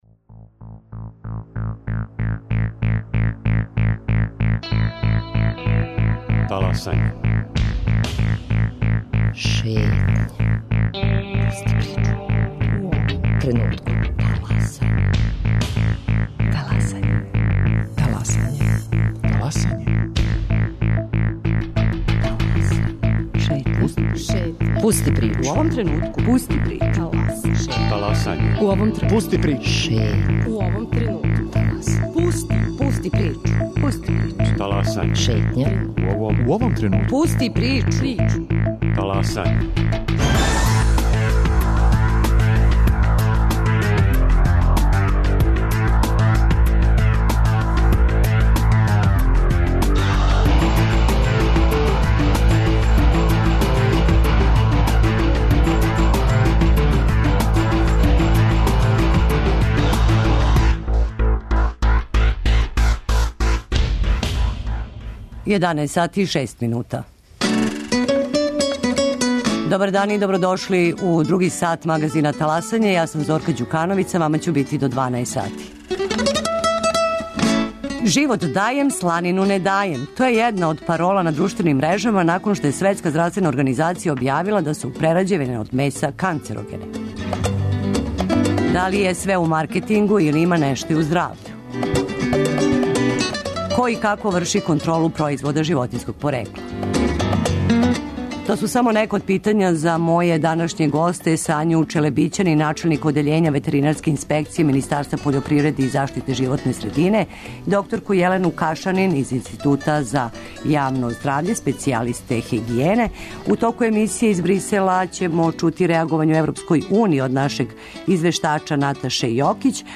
[ детаљније ] Све епизоде серијала Аудио подкаст Радио Београд 1 Ромска права у фокусу Брисела Хумористичка емисија Хумористичка емисија Хумористичка емисија Спортско вече, фудбал: Црвена Звезда - Партизан, пренос